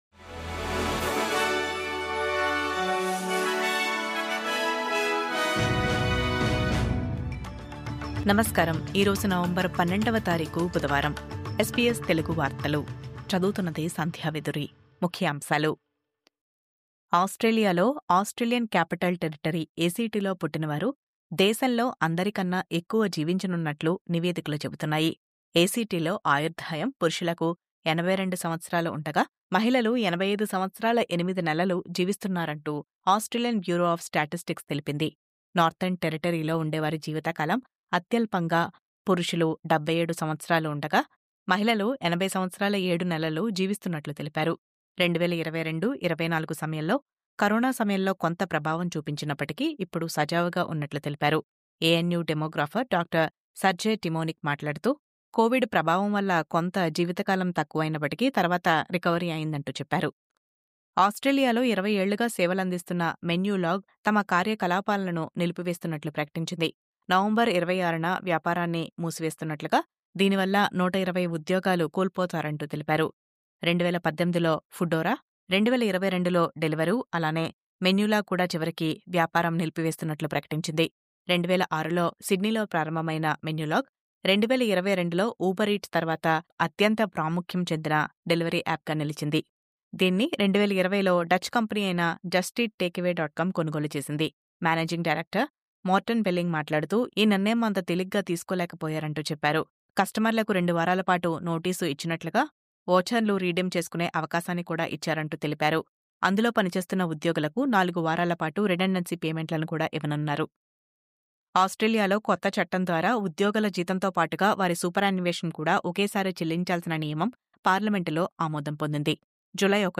News update: దేశంలో అందరికంటే ఎక్కువ కాలం జీవించే రాష్ట్రంగా A-C-T నిలిచింది...